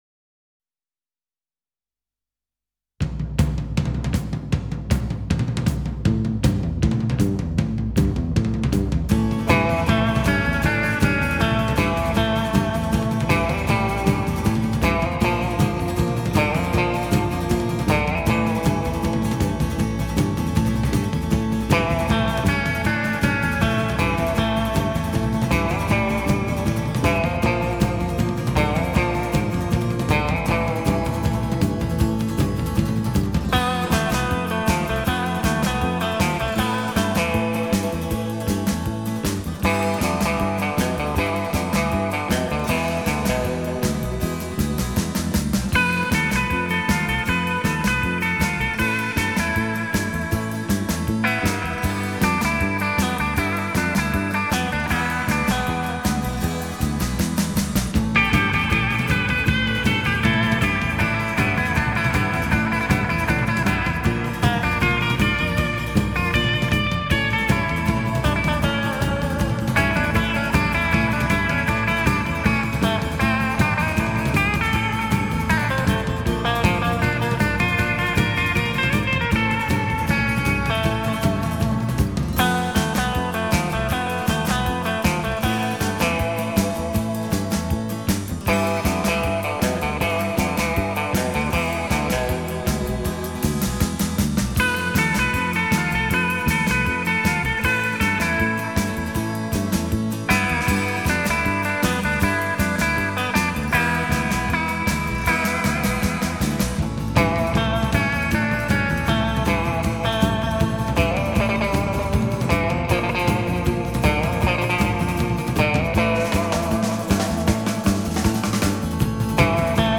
Винил